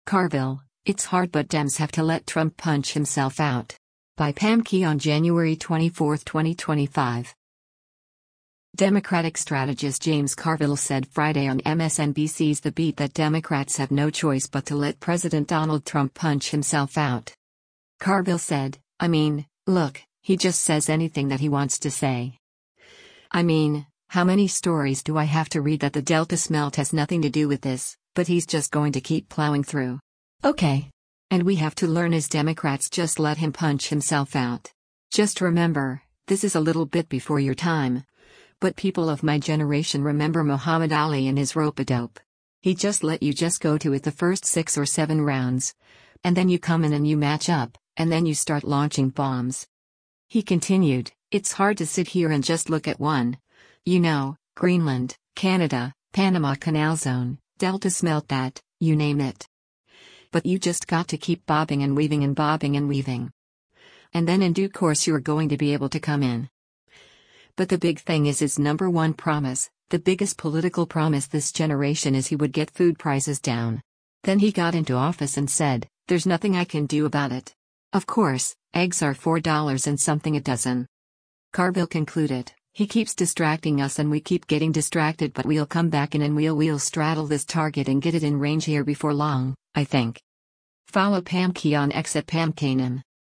Democratic strategist James Carville said Friday on MSNBC’s “The Beat” that Democrats have no choice but to let President Donald Trump “punch himself out.”